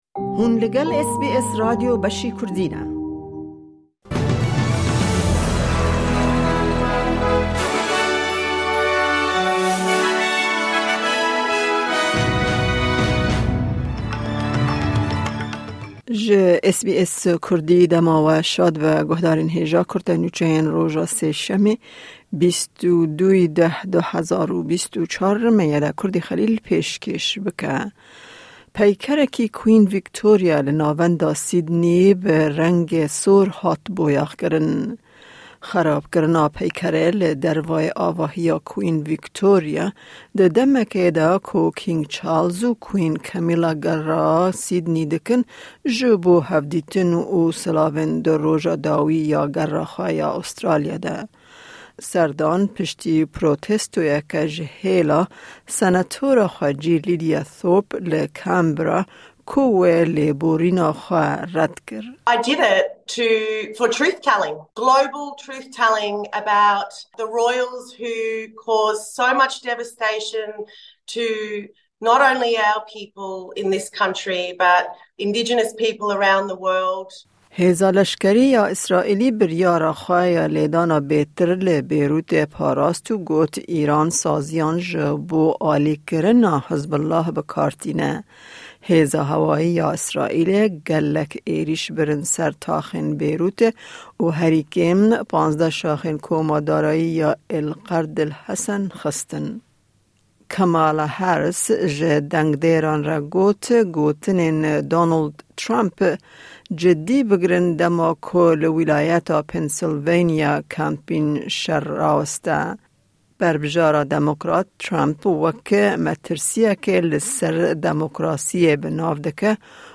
Kurte Nûçeyên roja Sêşemê 22î Cotmeha 2024